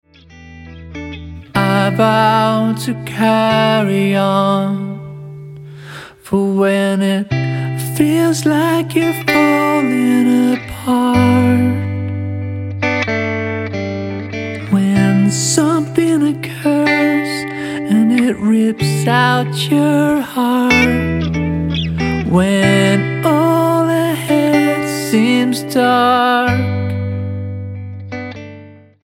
STYLE: Roots/Acoustic
a haunting harmonica accompaniment